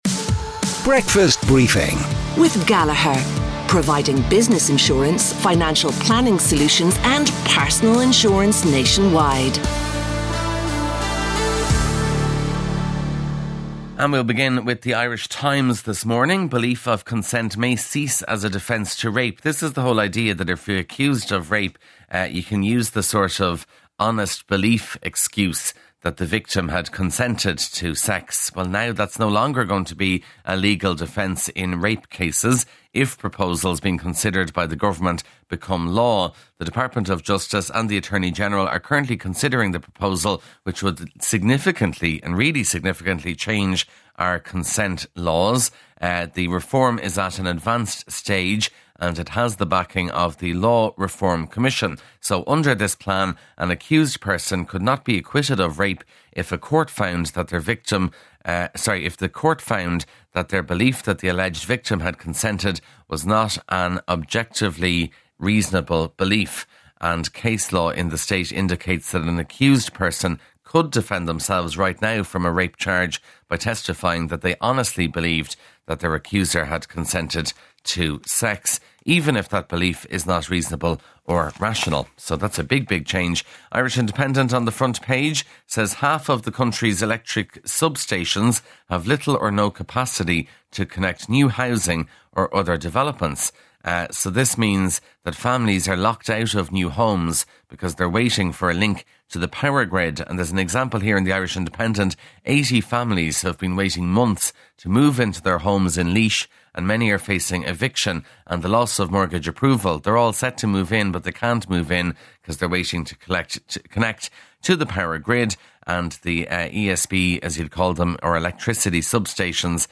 breakfast_briefing_newspaper_rev_e65cf6ae_normal.mp3